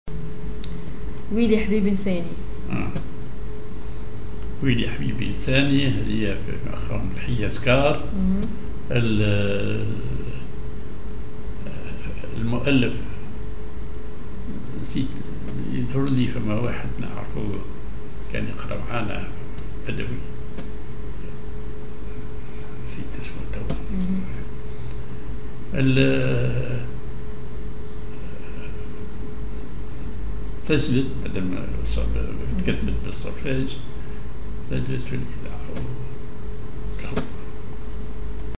Maqam ar حجاز كار
genre أغنية